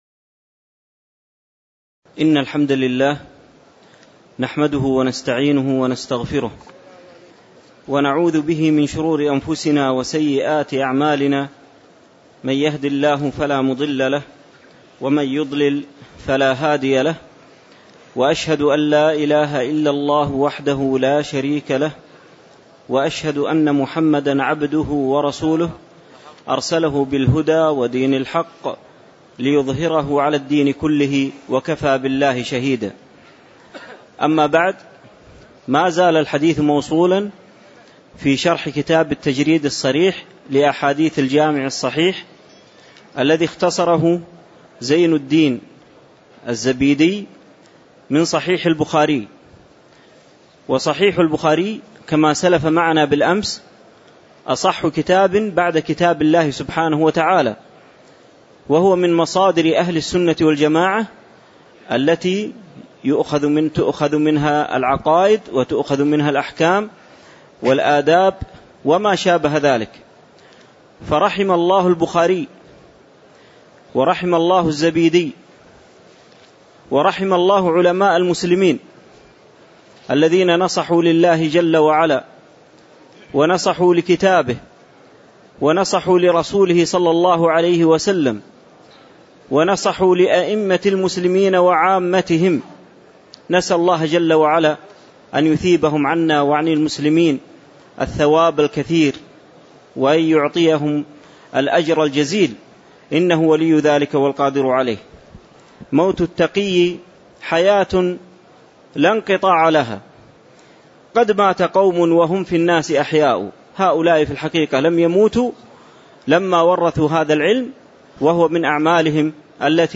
تاريخ النشر ٩ ذو القعدة ١٤٣٧ هـ المكان: المسجد النبوي الشيخ